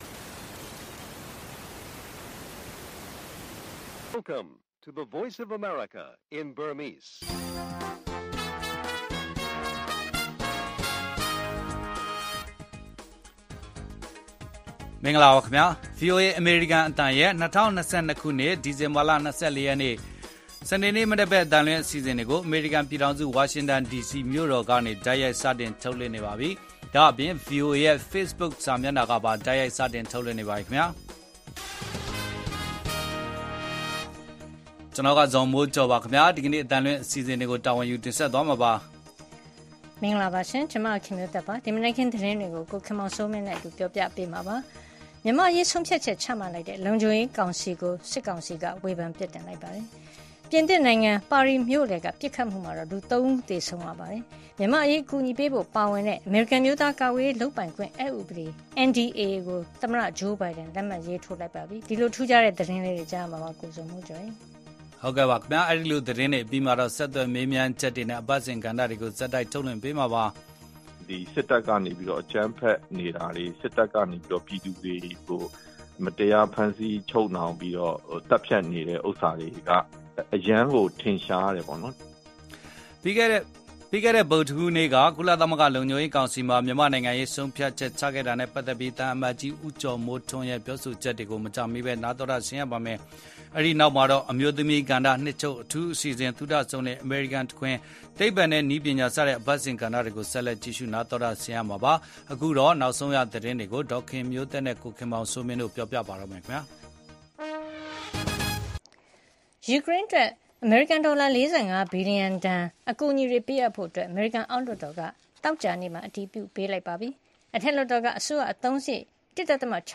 ကော့ကရိတ်မှာတိုက်ပွဲတွေဆက်လက်ပြင်းထန်၊ လုံခြုံရေးကောင်စီကို စစ်ကောင်စီကဝေဘန်၊ အနှစ် ၄၀ အတွင်းအဆိုးဆုံးဆောင်ရာသီမုန်တိုင်းဒါဏ်အမေရီးကားခံစားနေရပါတယ်။ လုံခြုံရေးကောင်စီဆုံးဖြတ်ချက်သံ အမတ်ကြီးဦးကျော်မိုးထွန်းနဲ့မေးမြန်းခန်း၊ အမျိုးသမီးကဏ္ဍနှစ်ချုပ်အထူးအစီအစဉ်၊ အမေရိကန်နိုင်ငံရေး၊ သိပ္ပံနဲ့နည်း ပညာ။